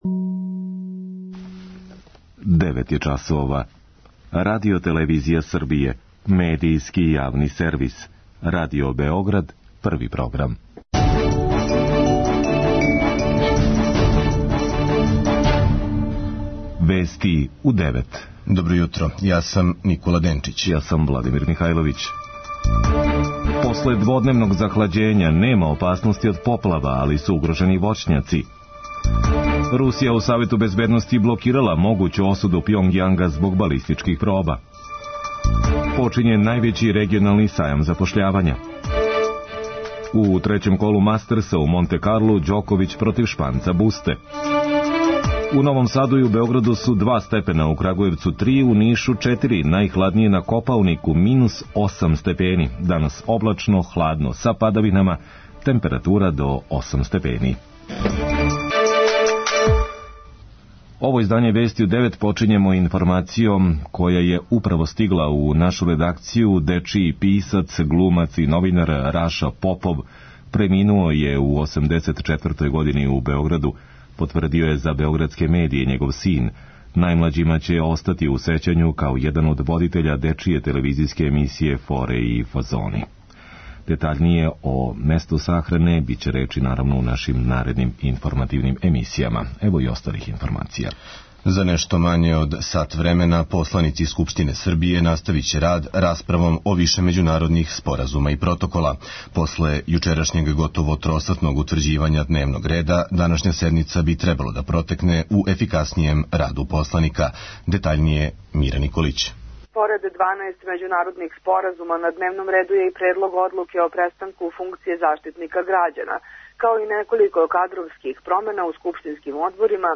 преузми : 3.69 MB Вести у 9 Autor: разни аутори Преглед најважнијиx информација из земље из света.